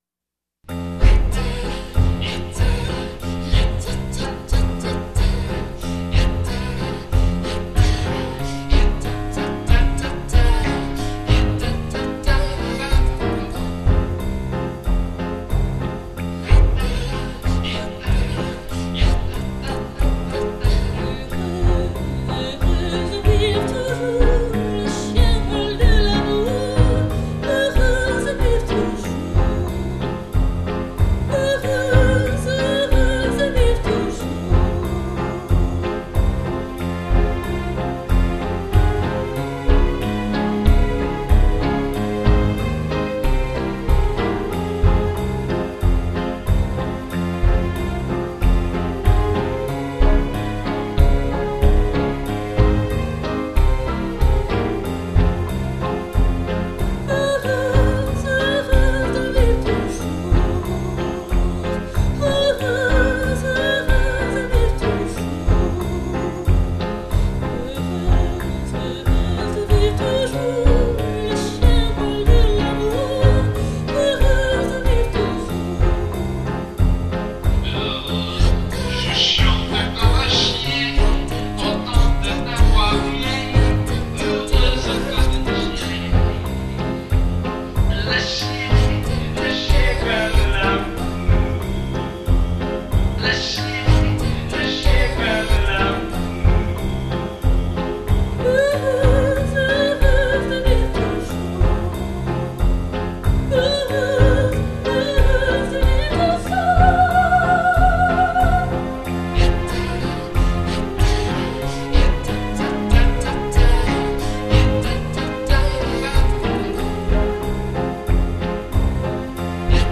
A song with French lyric in a cabaret style show for a mixed-language show.